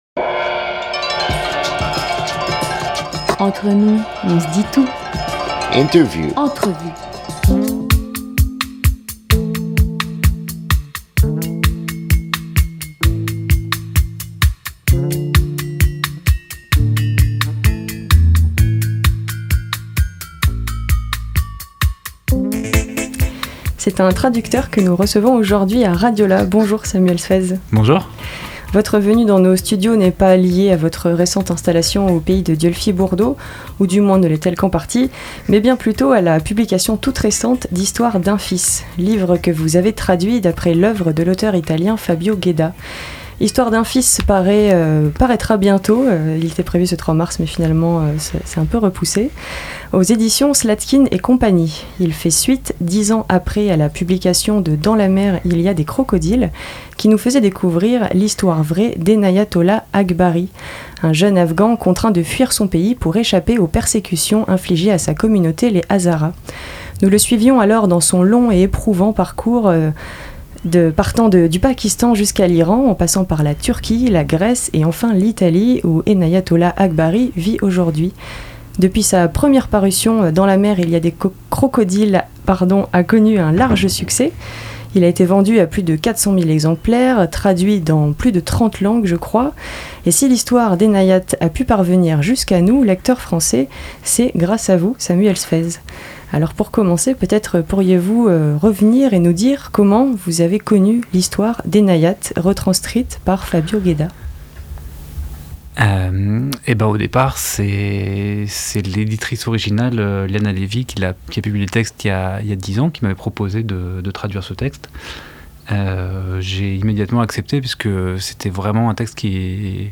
25 juillet 2022 15:40 | Interview